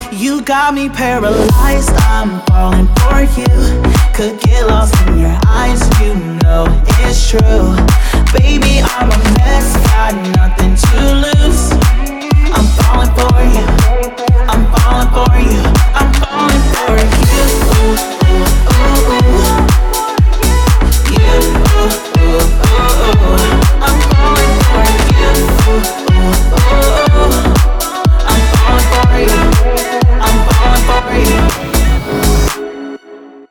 Классный slap house